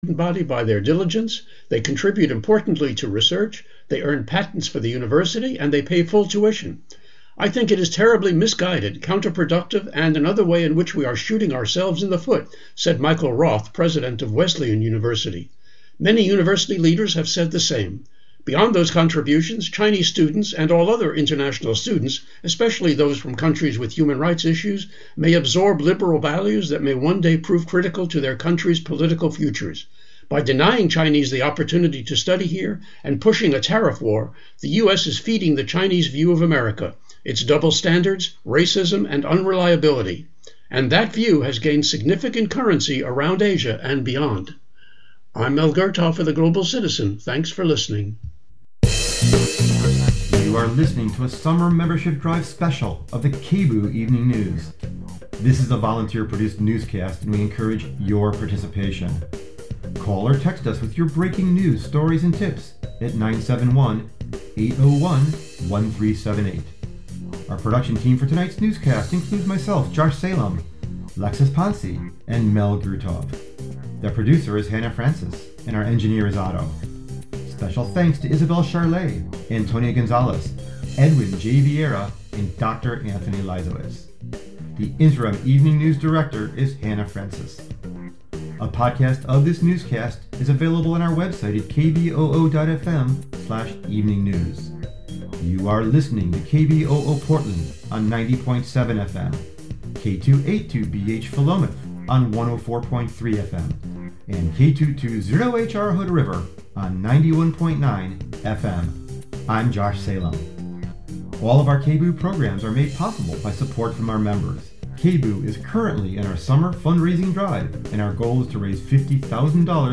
Hosted by: KBOO News Team